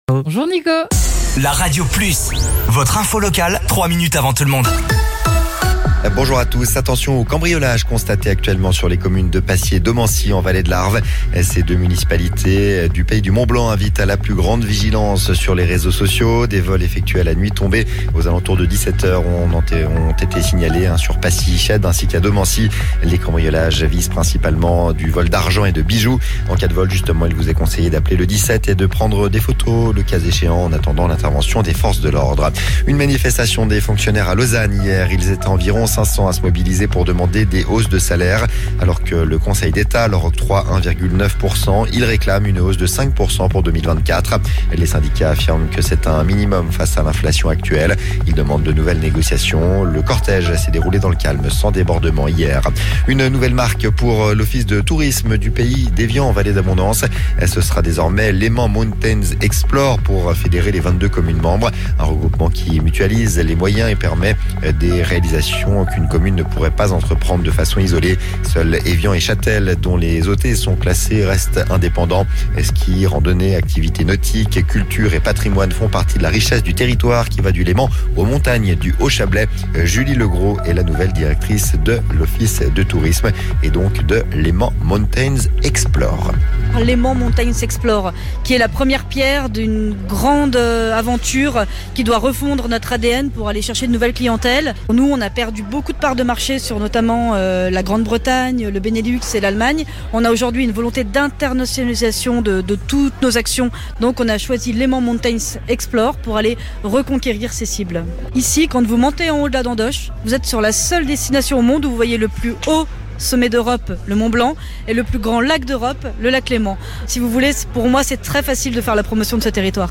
Votre flash info - votre journal d'information sur La Radio Plus